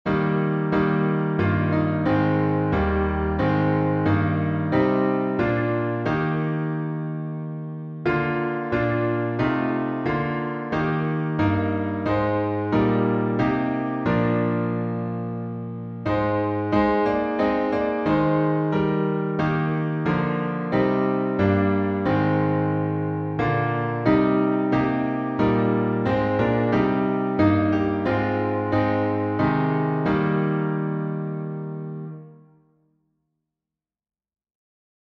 Be Thou My Vision — Alternate chording.
Words by Irish Hymn c. 8th. century Tune: SLANE, an Irish Melody Key signature: E fla
Key signature: E flat major (3 flats) Time signature: 3/4